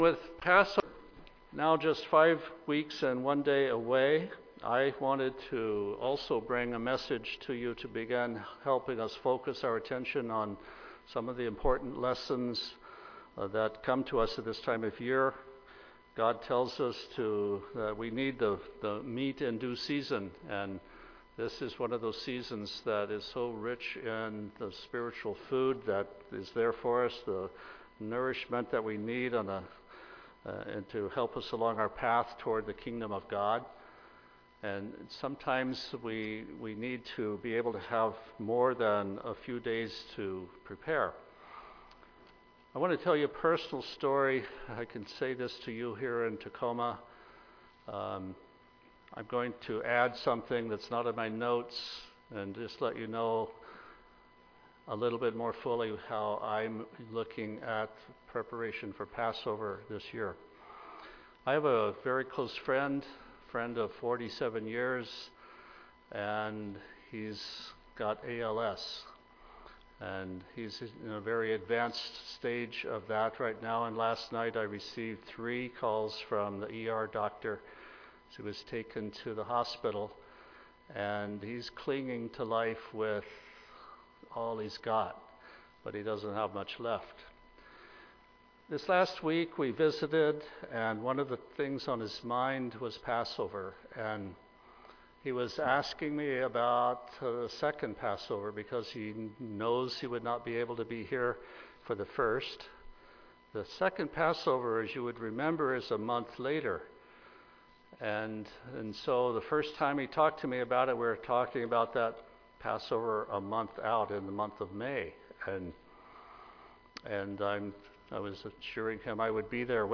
Sermons
Given in Tacoma, WA